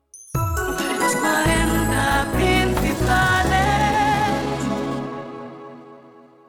Indicatiu curt